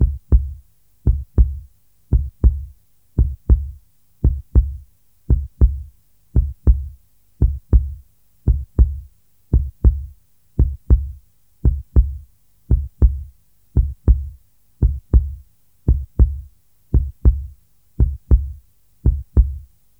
Heartbeat Free sound effects and audio clips
• human heartbeat 58 bpm.wav
human_heartbeat_58_bpm_lIO.wav